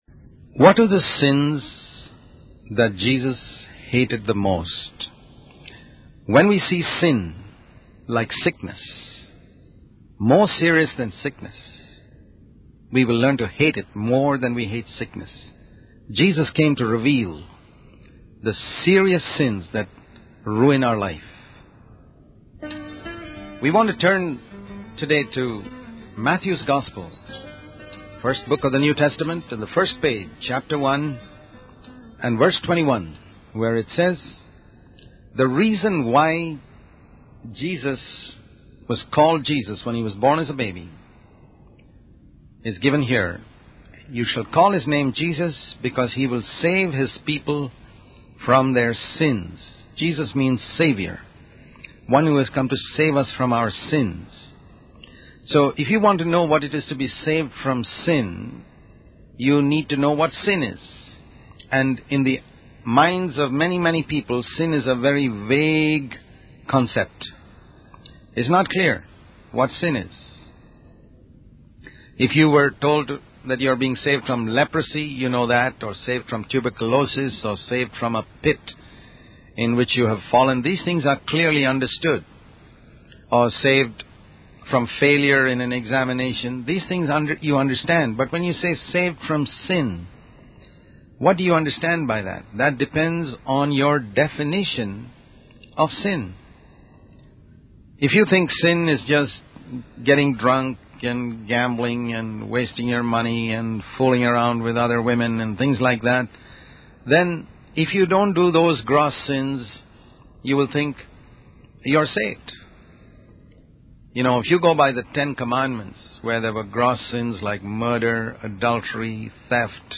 In this sermon, the speaker discusses the parable of the ten virgins who went to meet the bridegroom.